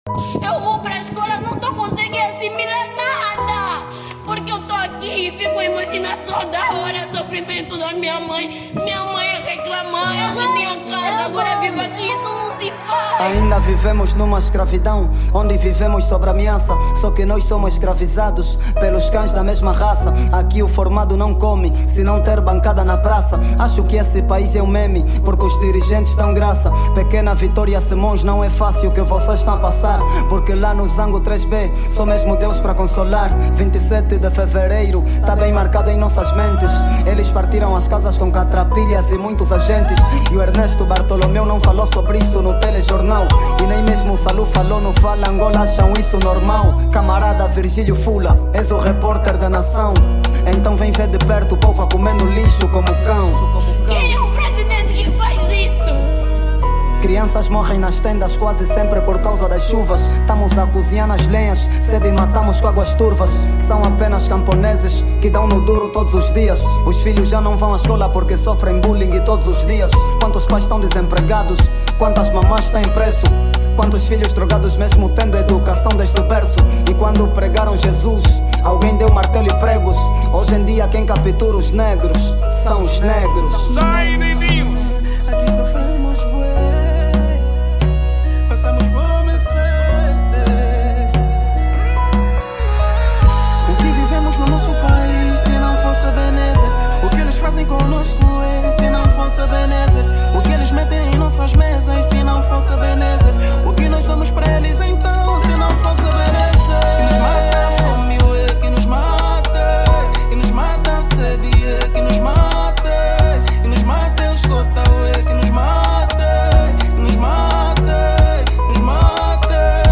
Genero:kuduro